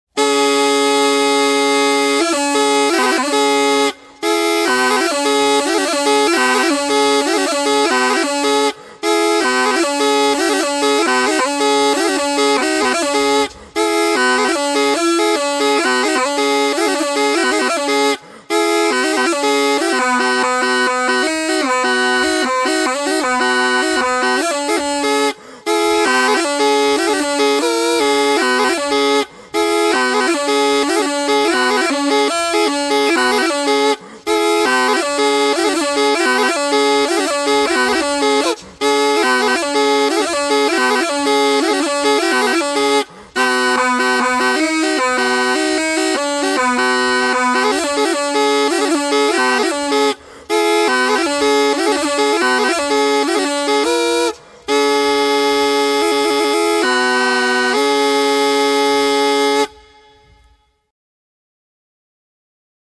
25_Наигрыш_на_двойных_пищиках.mp3